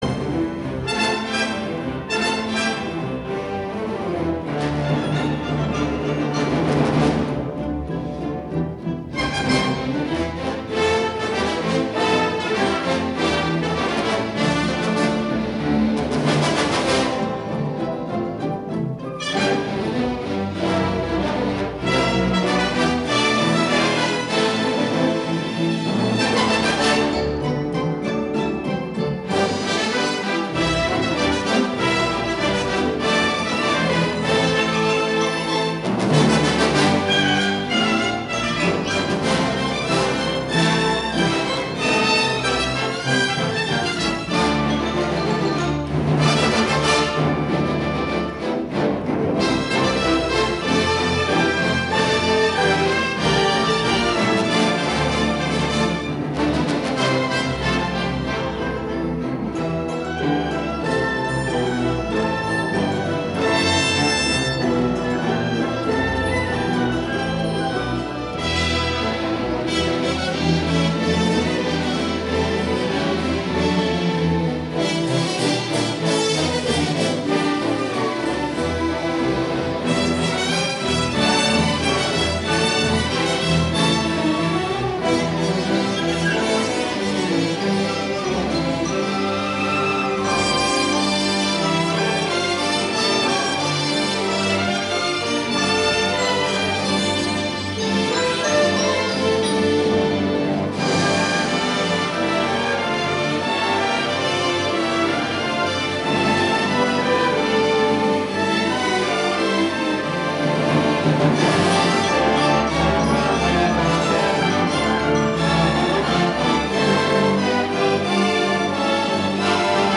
电影开始威风凛凛、洋洋洒洒的管弦乐传神地表现了壮士们的胆识和机智。